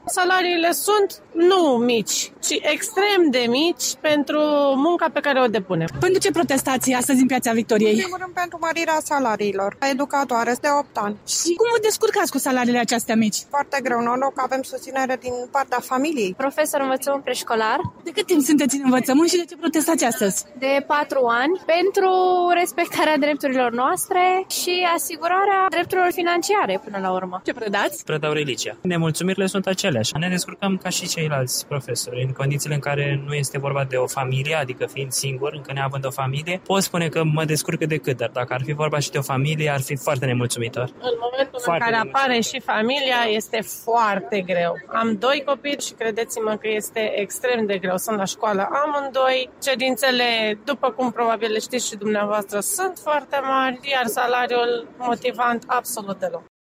Câţiva protestatari i-au explicat reporterului RRA